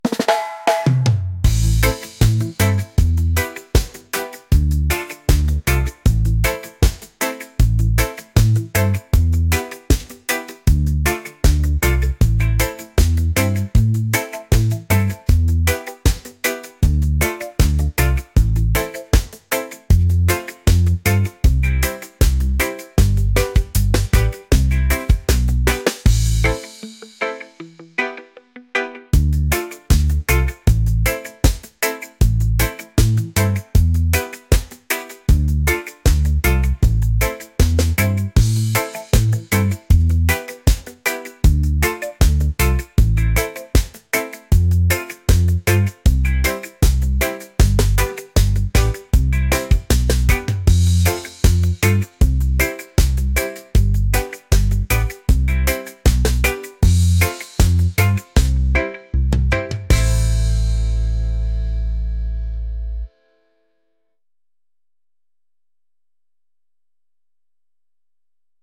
laid-back | upbeat | reggae